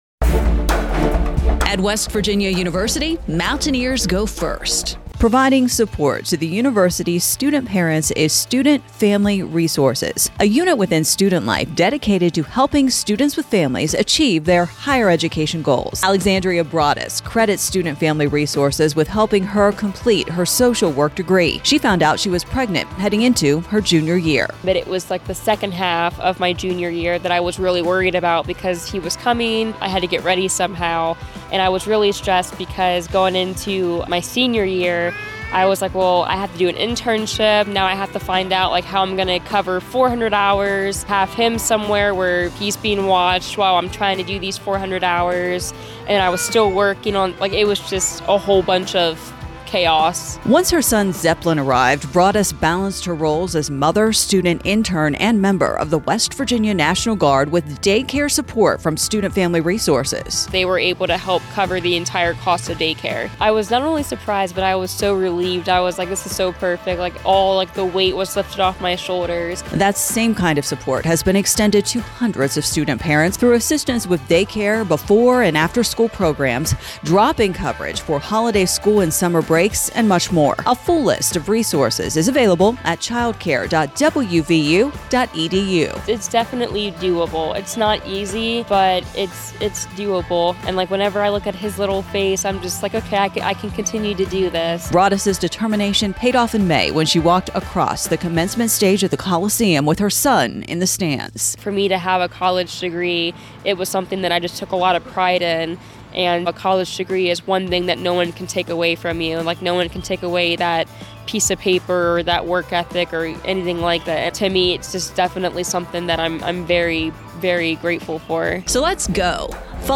student-family-resources-radio-spot.mp3